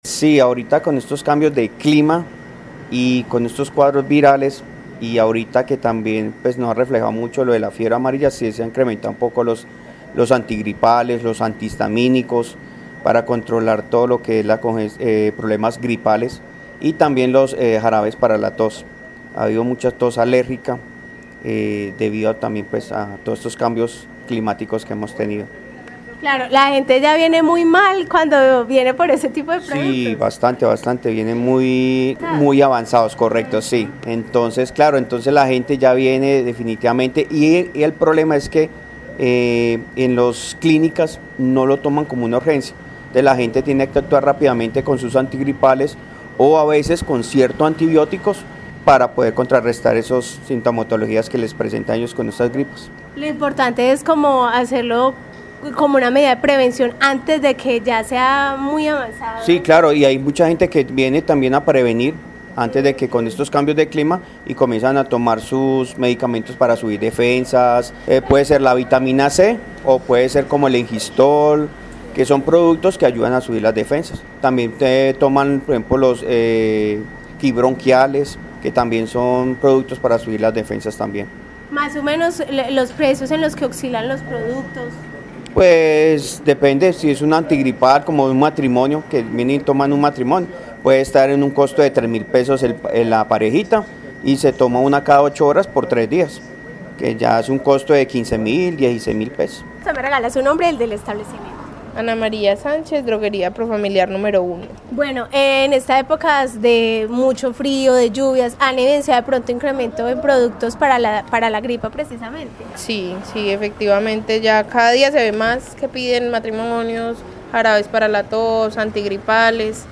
Informe droguerias sintomas gripales
Caracol Radio Armenia salió a las calles de la ciudad para conocer cuál es la situación en las droguerías en cuanto a los productos para aliviar la gripa y la tos debido al virus que circula actualmente.